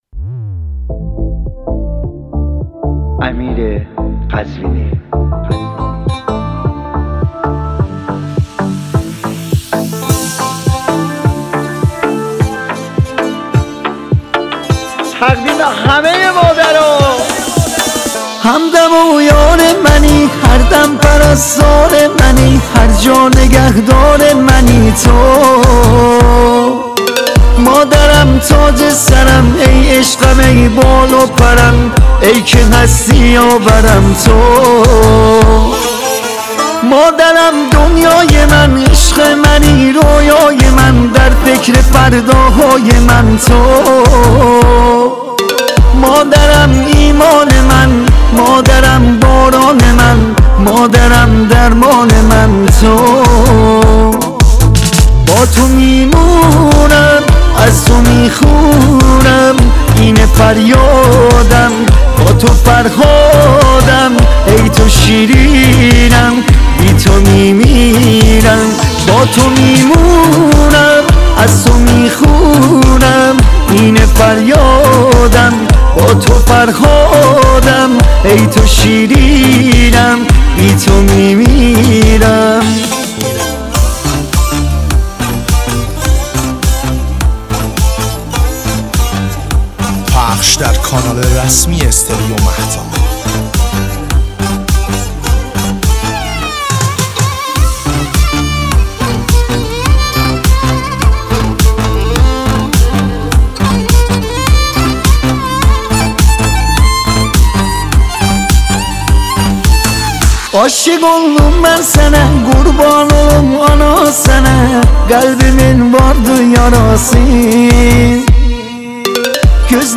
آهنگ ترکی شاد مادر